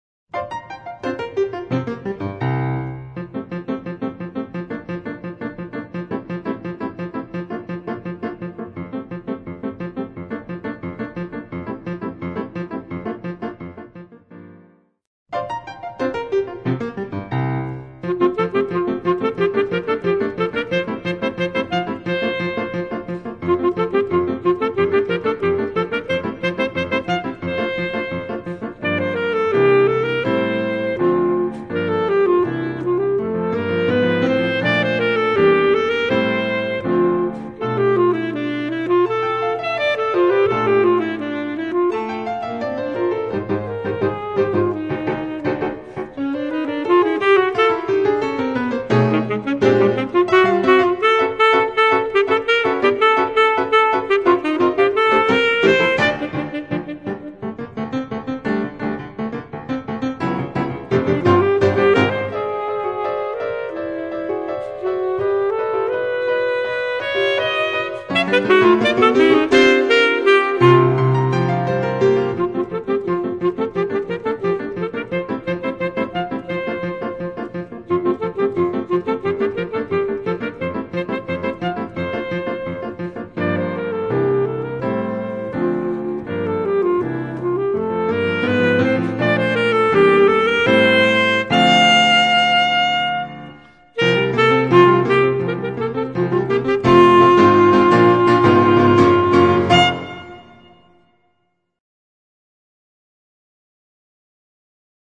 Solo für Flöte, oder Klarinette, oder Altsaxophon + Klavier.